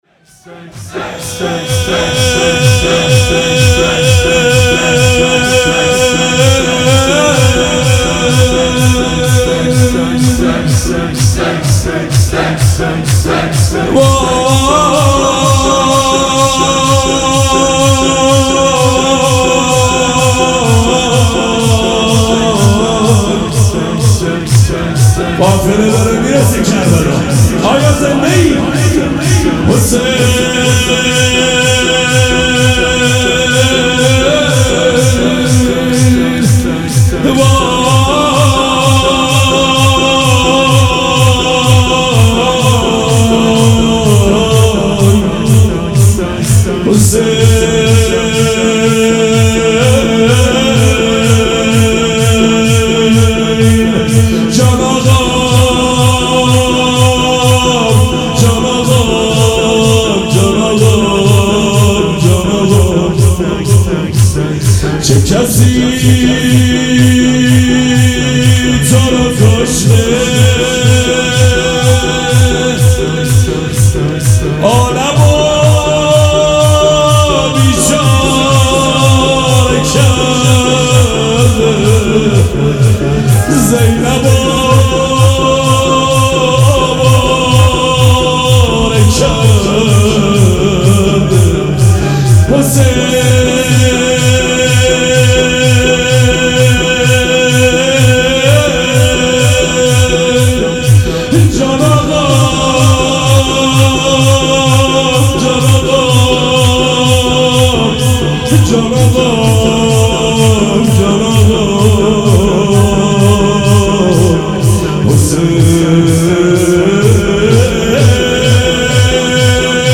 مراسم عزاداری شهادت امام جواد علیه‌السّلام
شور